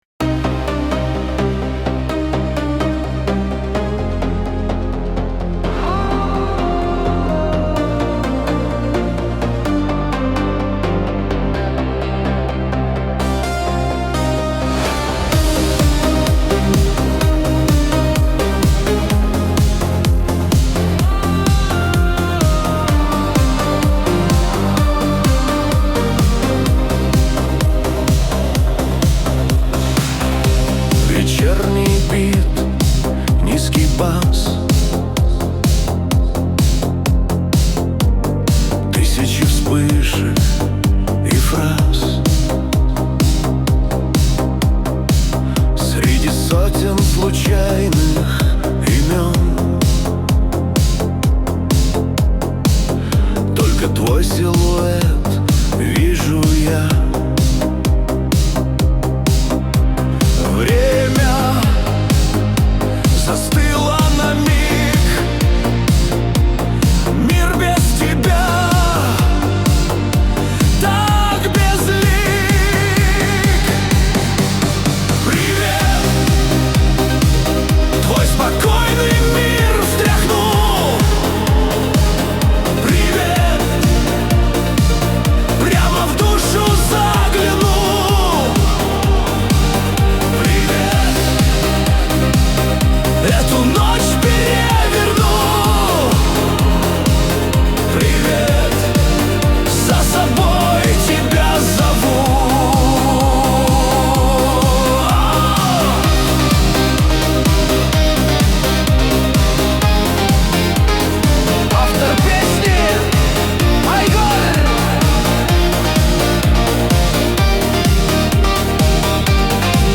созданный с помощью искусственного интеллекта.